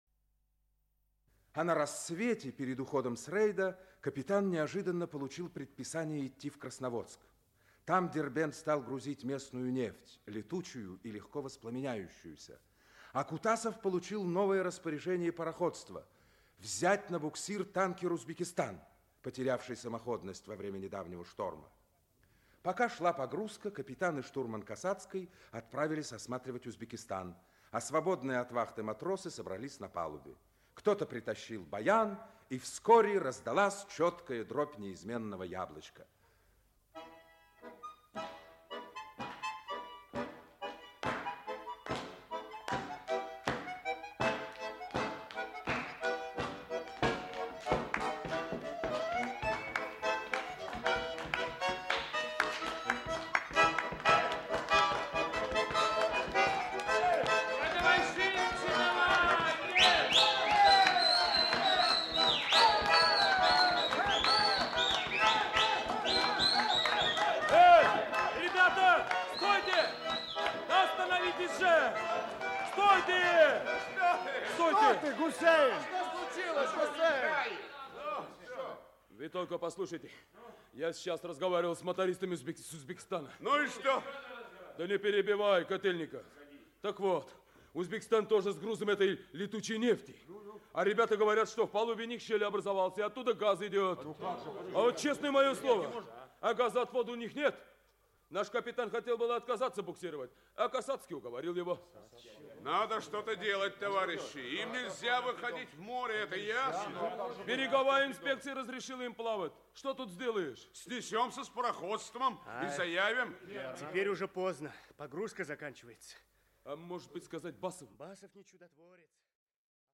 Аудиокнига Танкер «Дербент». Часть 2 | Библиотека аудиокниг
Aудиокнига Танкер «Дербент». Часть 2 Автор Юрий Крымов Читает аудиокнигу Актерский коллектив.